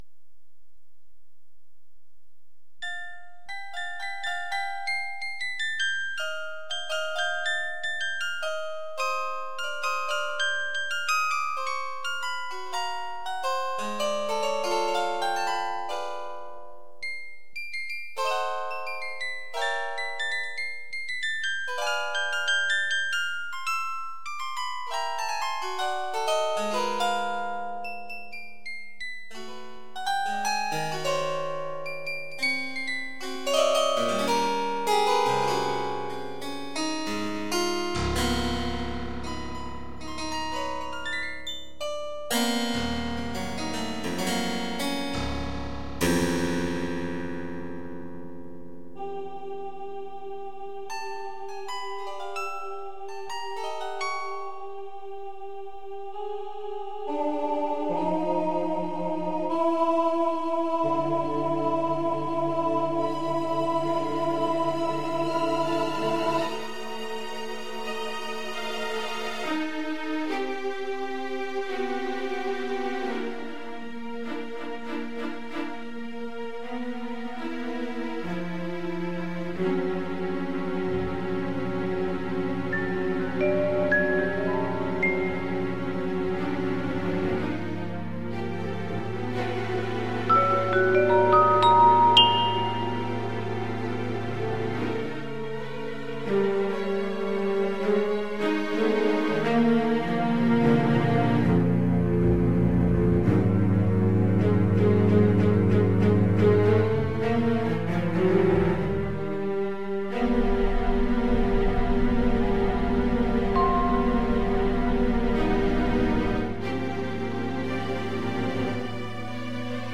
Музыка для глубоких и пронзительных стихов